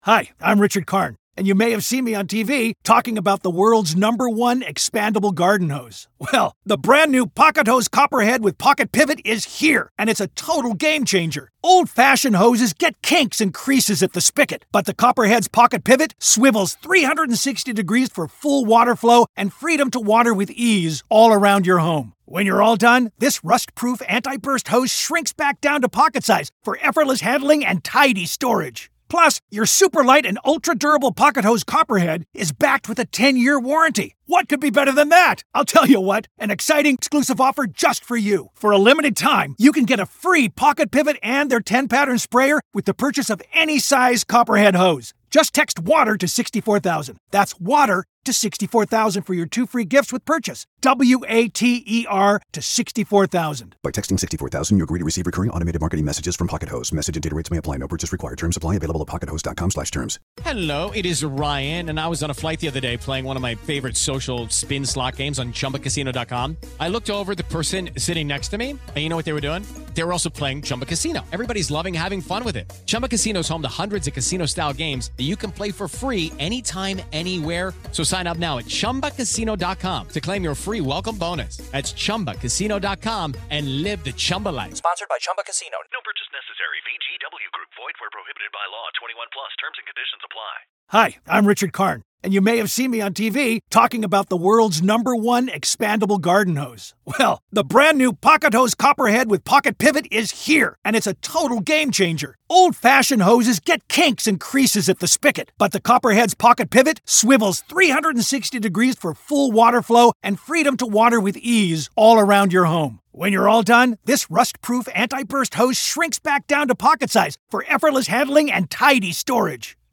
The conversation underscores the importance of safety protocols and accountability on film sets.